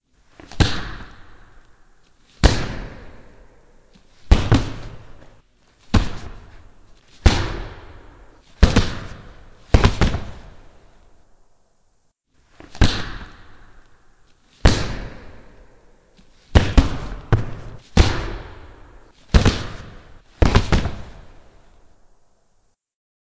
真实世界的声音 " 戴着手套抓人和打人
描述：用手套抓住和打孔的声音。
标签： 冲压 皮肤 手套 拳击 抢夺 冲击 冲击
声道立体声